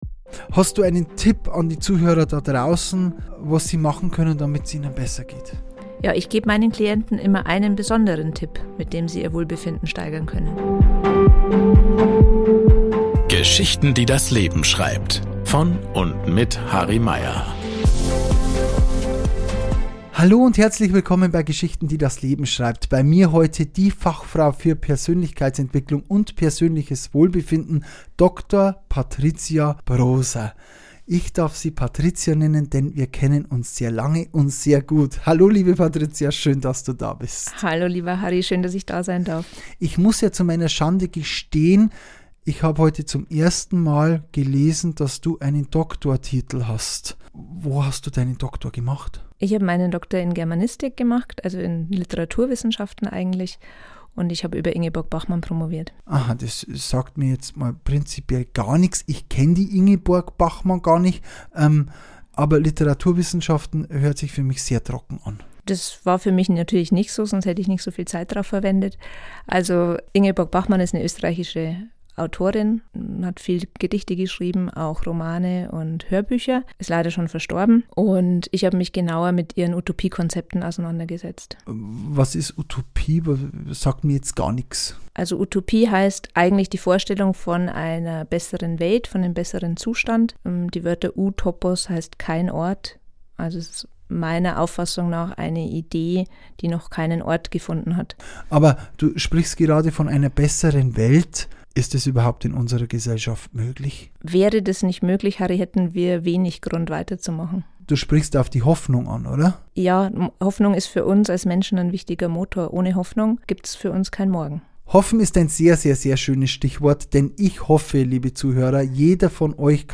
Ein Gespräch über Grenzen, Neubeginn, innere Stärke – und die kleinen Dinge, die Großes bewirken können.